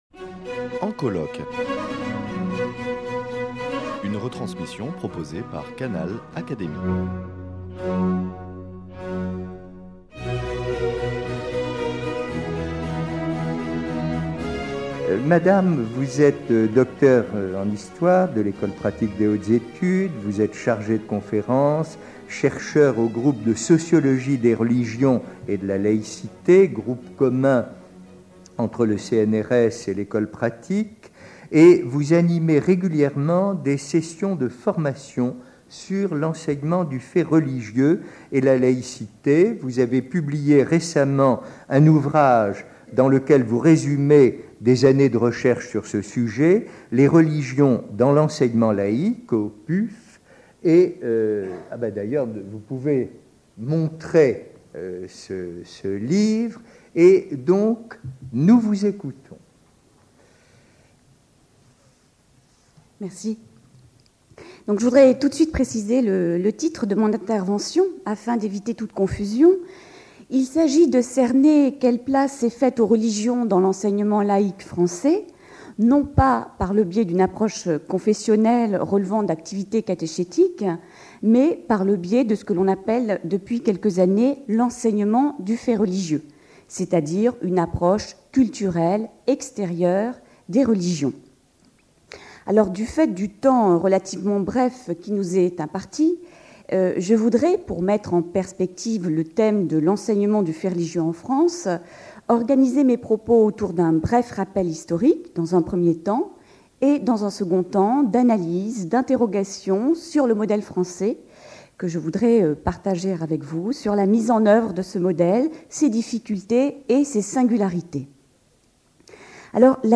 Le religieux et l’enseignement en France et en Europe : tel était l’un des thèmes abordés lors du 4e et dernier colloque sur la loi 1905, organisé par l’Académie des sciences morales et politiques.